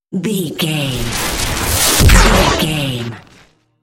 Whoosh to hit technology
Sound Effects
dark
futuristic
high tech
intense
woosh to hit